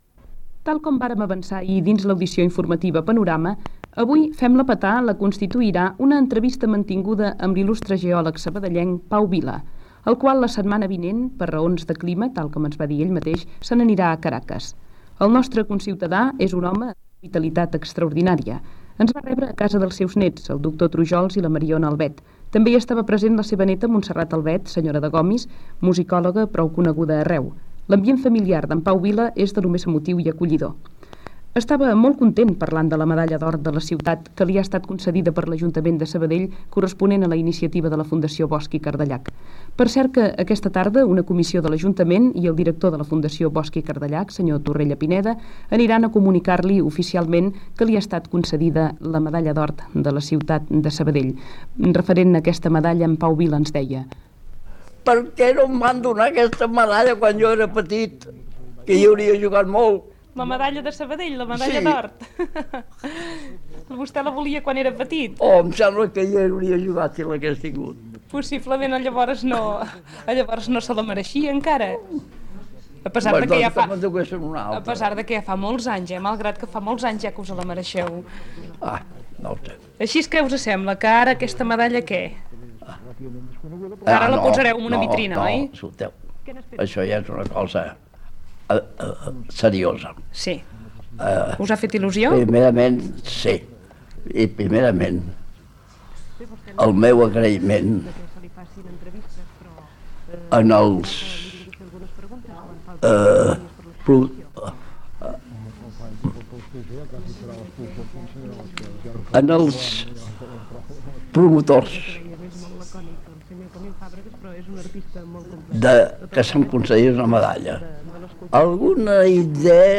Entrevista feta a la casa de Pau Vila, geòleg de la ciutat que havia estat distingit amb la medalla d'or de Sabadell